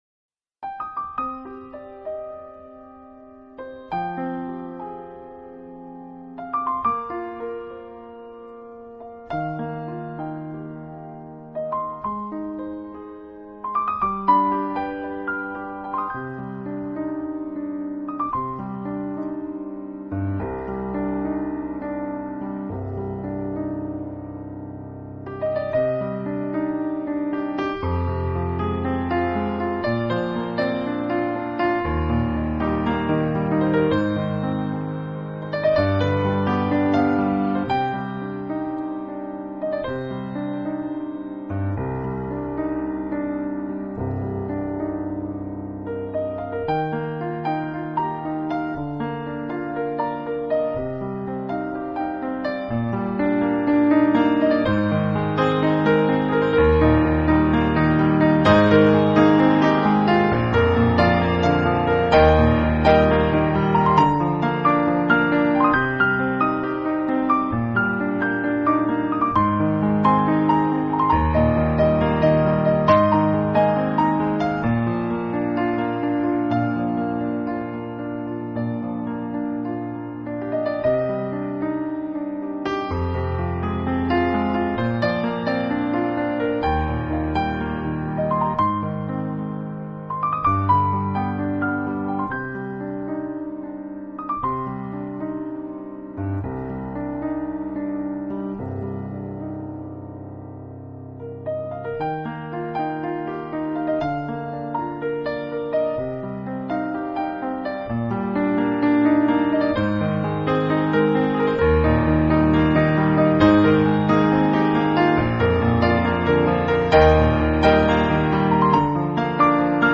Styles: New Acoustic/Piano solo, New Age/Meditative